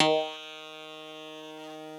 genesis_bass_039.wav